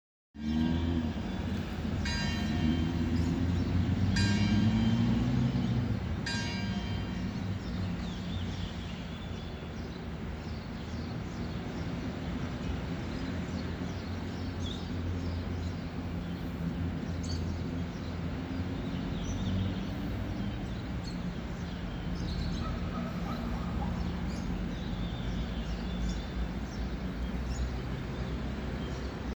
Travel Sounds
That classic Roman cathedral sound